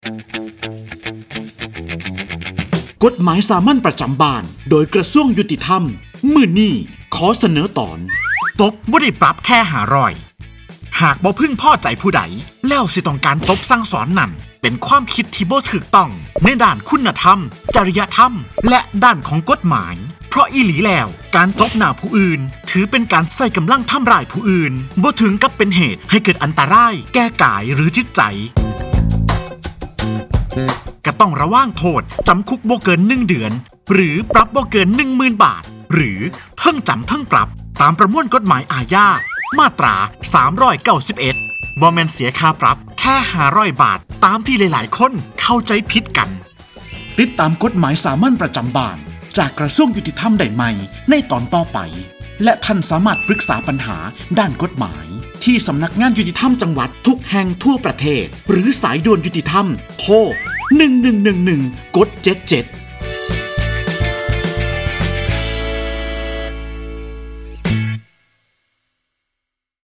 กฎหมายสามัญประจำบ้าน ฉบับภาษาท้องถิ่น ภาคอีสาน ตอนตบไม่ได้ปรับแค่ 500
ลักษณะของสื่อ :   คลิปเสียง, บรรยาย